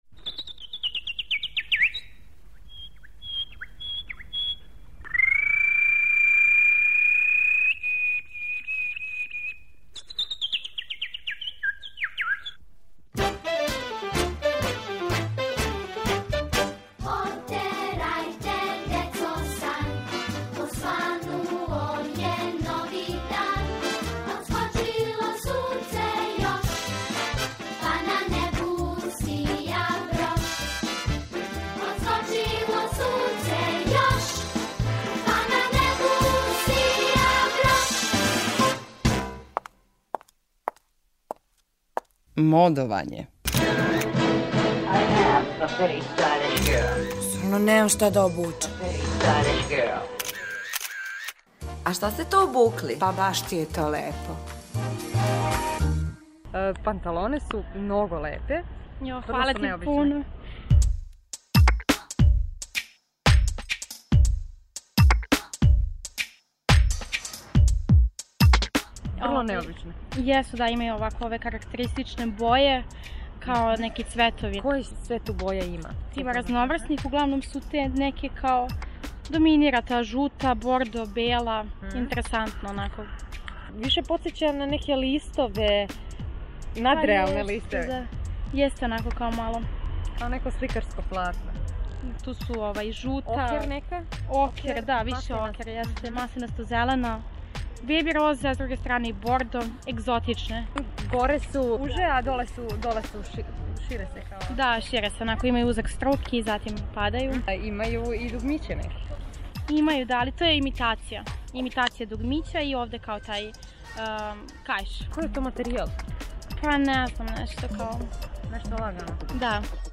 У серијалу Модовање питамо занимљиво одевене случајне пролазнике да опишу своју одевну комбинацију.